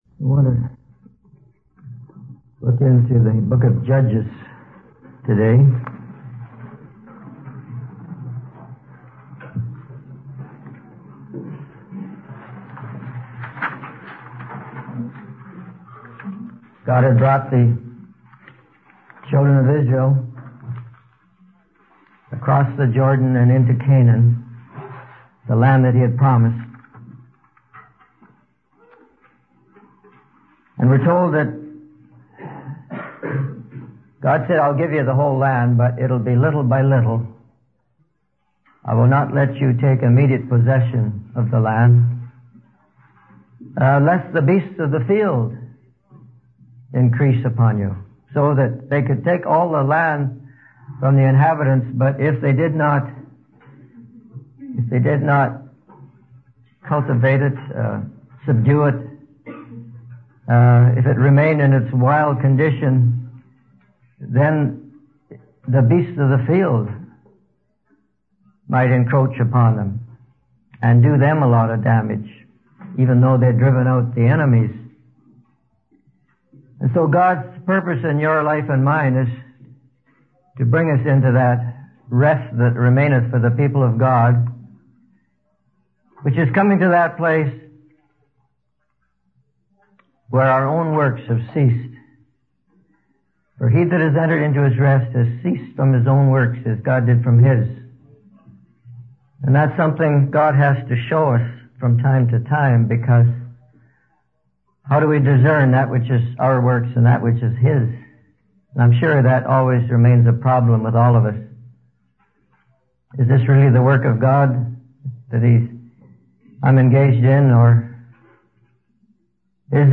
In this sermon, the preacher discusses the book of Judges and how God allowed areas of resistance to remain in the land so that the younger generation could learn war. The preacher emphasizes that God's principles of truth must be established within us so that we are ready for any challenges that come our way.